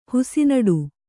♪ husi naḍu